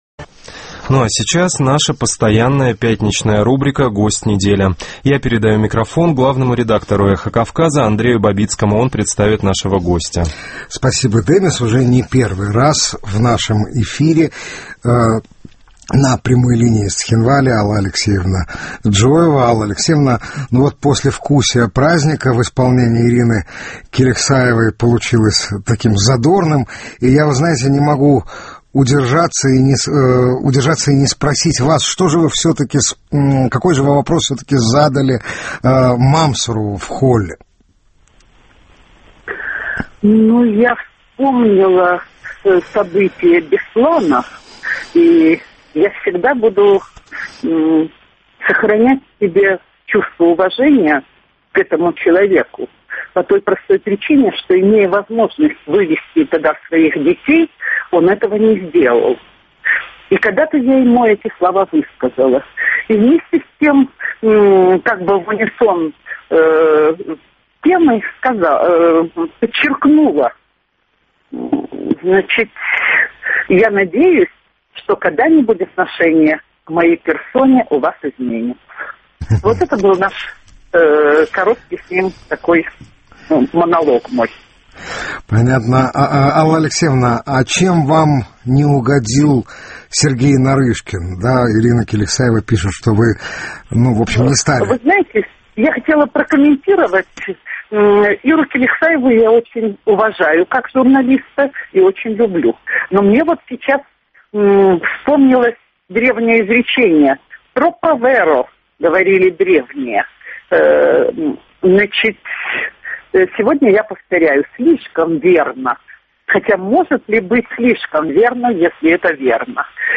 Уже не первый раз в нашем эфире на прямой линии из Цхинвали Алла Алексеевна Джиоева.